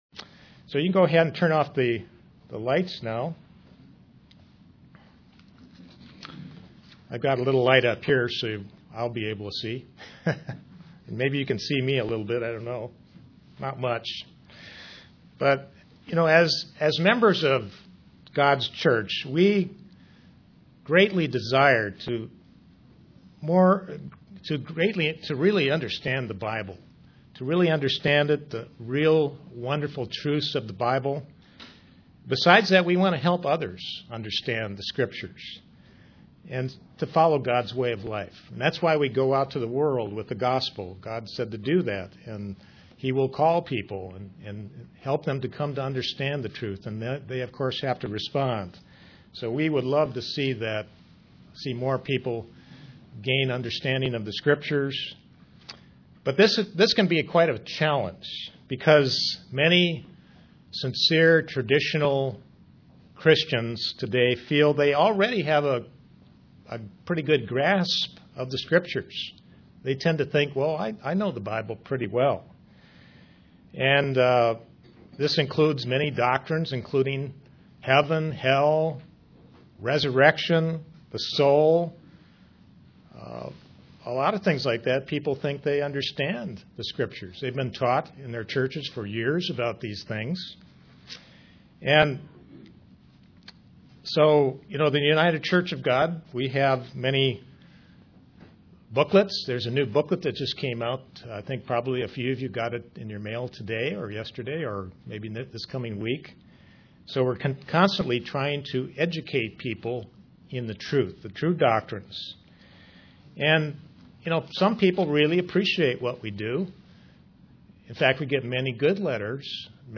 Given in Kingsport, TN
Print Study of the story of Lazarus and the rich man UCG Sermon Studying the bible?